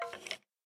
骷髅：咯咯声
空闲时随机播放这些音效
Minecraft_Skeleton_say2.mp3